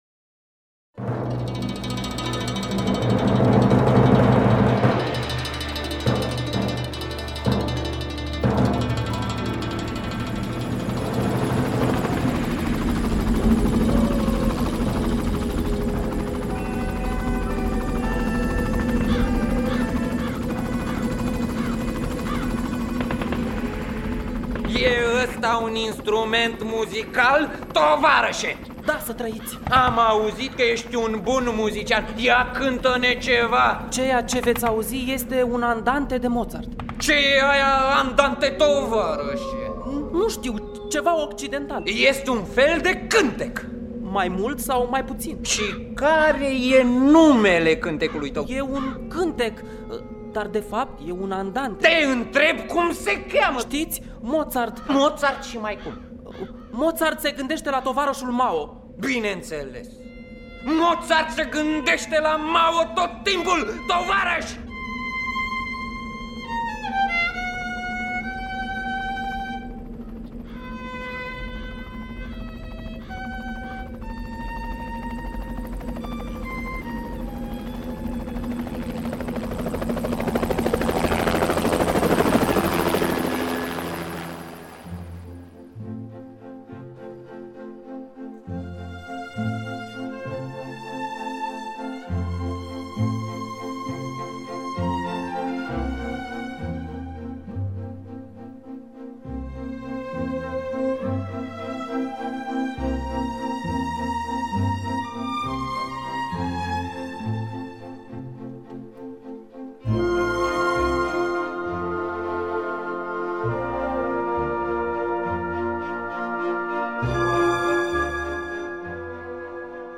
Amadeus de Peter Shaffer – Teatru Radiofonic Online